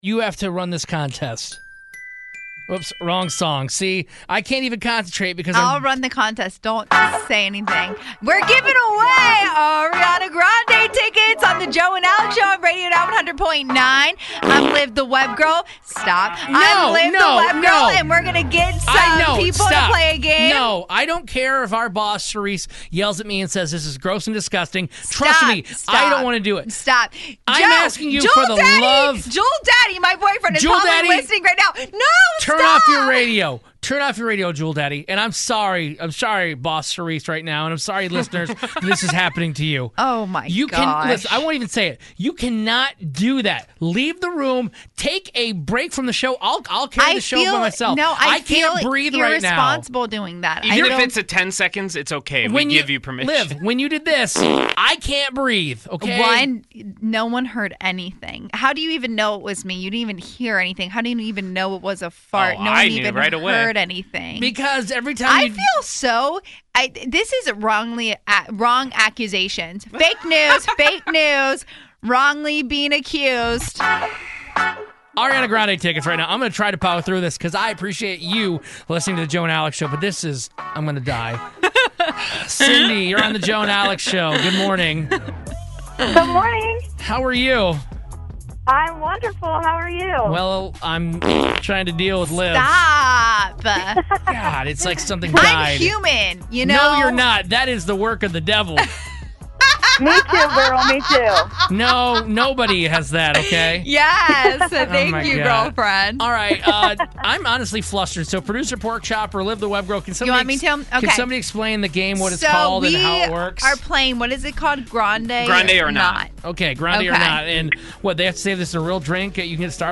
We played a game with a caller to win Ariana Grande tickets!! Was the drink an actual Starbucks drink or was it made up?!